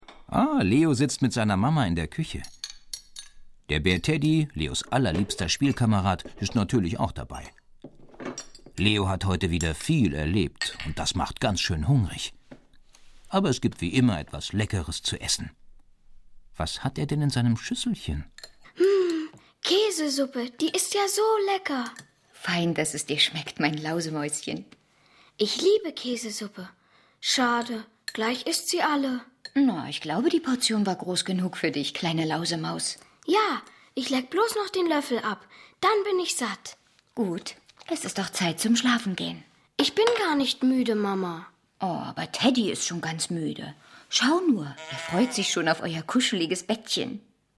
Ravensburger Leo Lausemaus - Folge 1 ✔ tiptoi® Hörbuch ab 3 Jahren ✔ Jetzt online herunterladen!